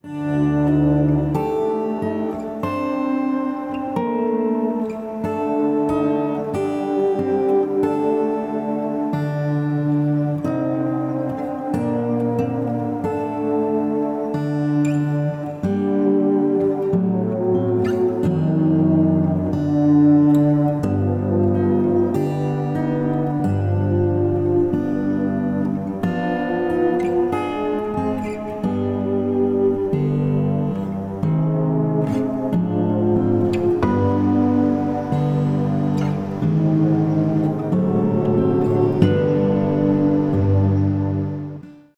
12-string LucyTuned guitar
Audio (.wav) (7 MB) - 41 seconds of Final Mix